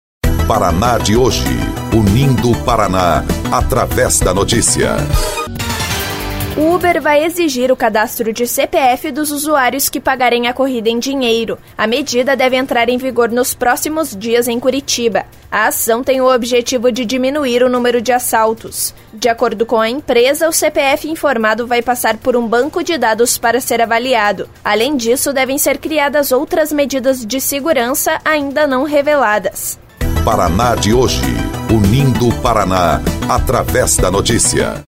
BOLETIM – Uber vai exigir CPF para pagamento em dinheiro